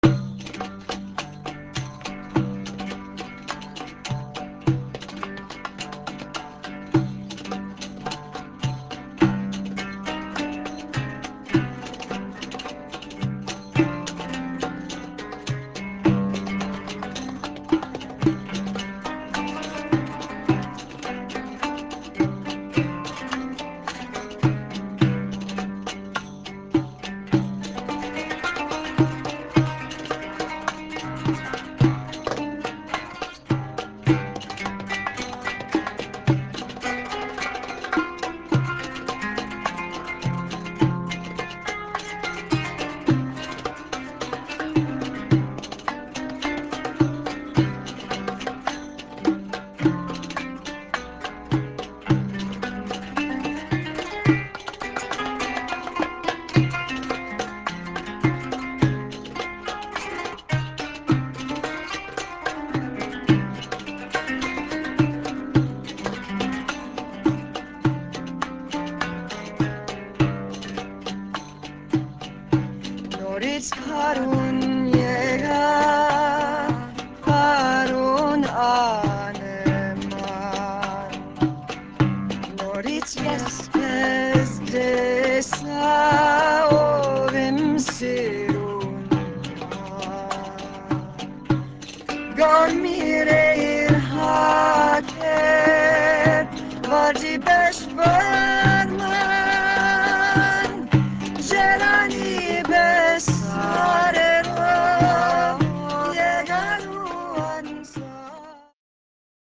qanun (zither)
doumbec and tambourine